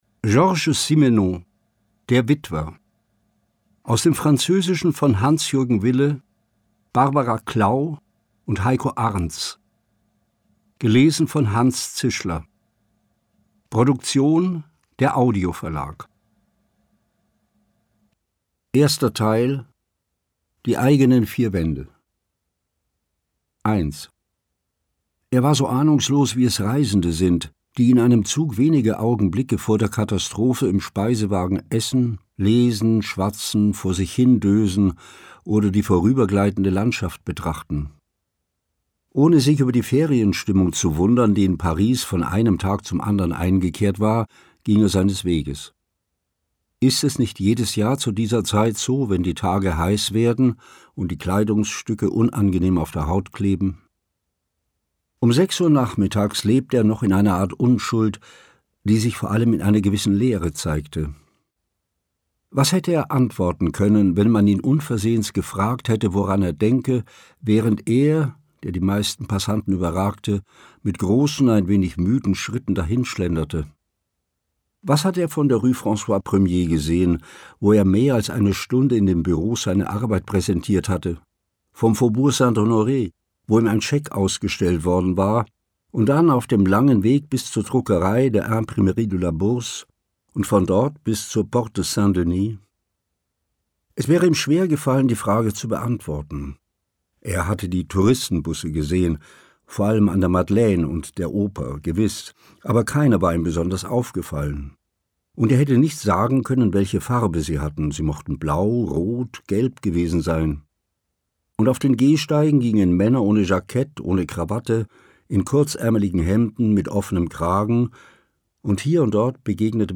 Hanns Zischler (Sprecher)
Ungekürzte Lesung